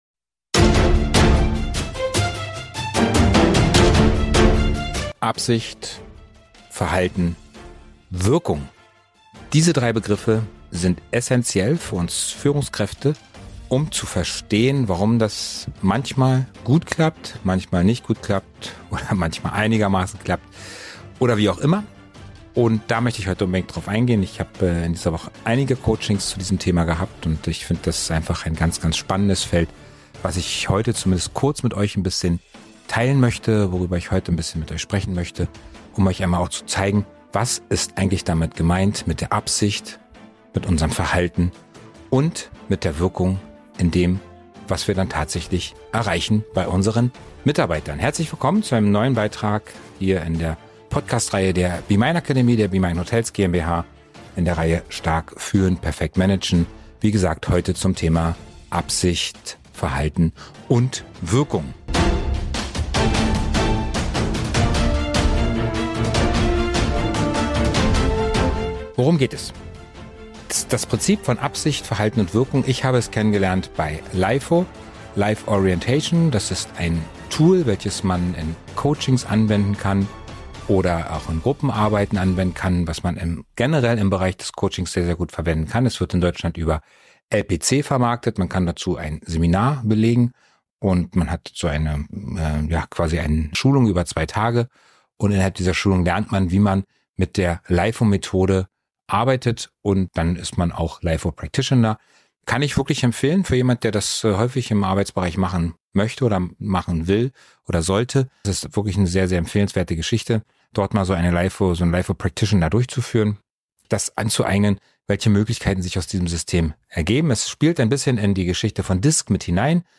Musik aus Youtube, lizenzfreie Musik.